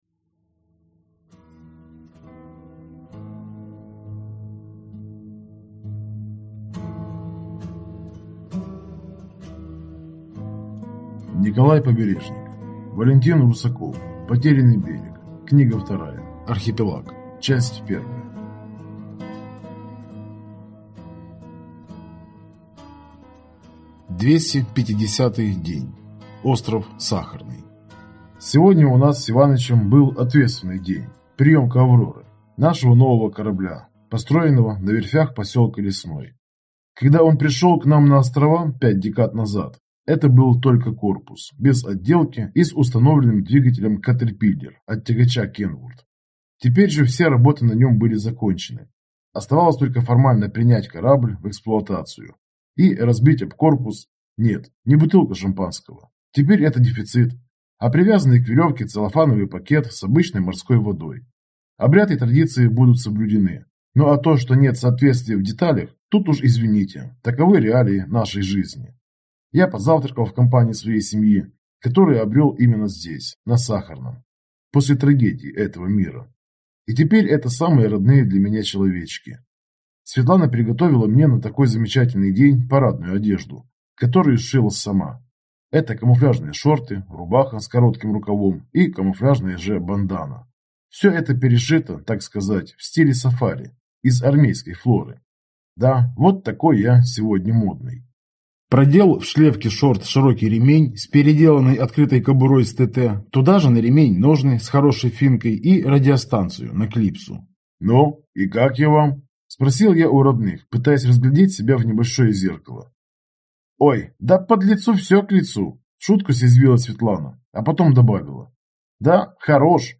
Аудиокнига Архипелаг | Библиотека аудиокниг